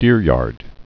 (dîryärd)